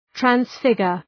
Προφορά
{træns’fıgər}